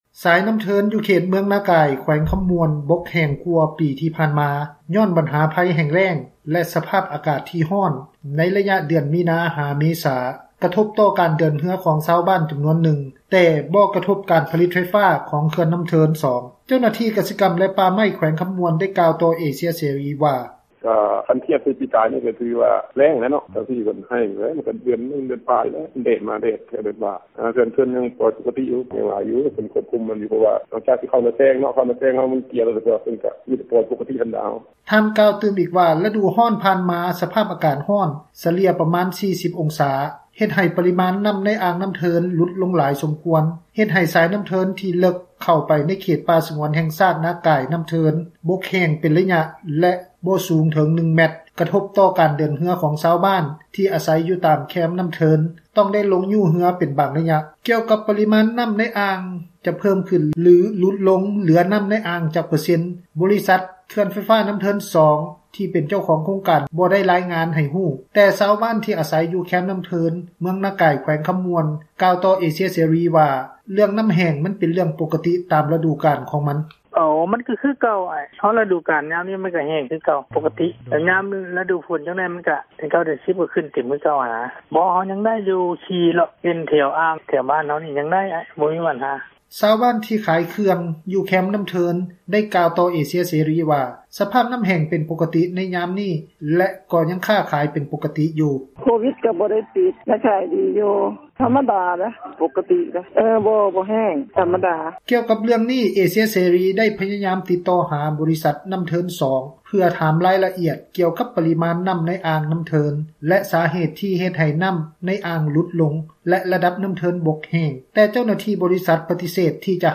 ເຈົ້າໜ້າທີ່ ກະສິກັມ ແລະ ປ່າໄມ້ ແຂວງຄຳມ່ວນ ໄດ້ກ່າວຕໍ່ເອເຊັຽເສຣີ ວ່າ:
ຊາວບບ້ານມີອາຊີບຂາຍເຄື່ອງ ຢູ່ແຄມອ່າງນ້ຳເທີນ ໄດ້ກ່າວຕໍ່ເອເຊັຽເສຣີວ່າ ສະພາບນ້ຳແຫ້ງເປັນປົກກະຕິໃນຍາມນີ້ ແລະກໍຍັງຄ້າຂາຍ ໄດ້ປົກກຕິ ຢູ່: